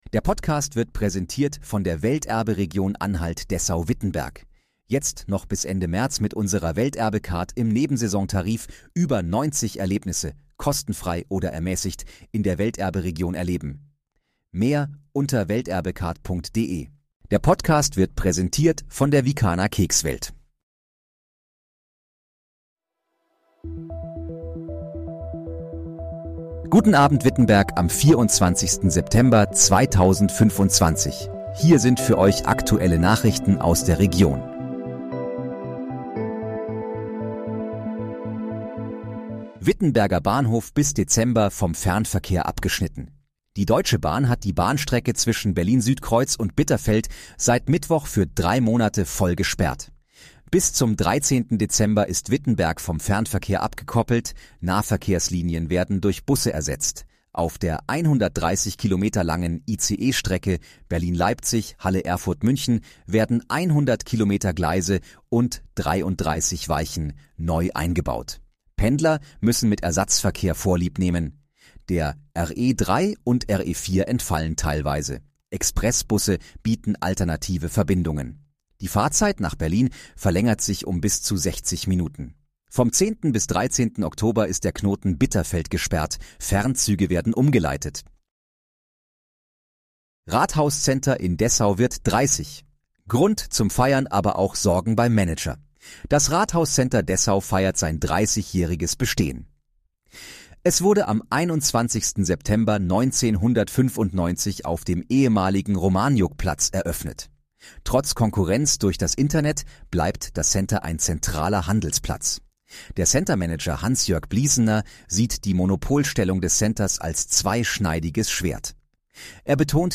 Guten Abend, Wittenberg: Aktuelle Nachrichten vom 24.09.2025, erstellt mit KI-Unterstützung
Nachrichten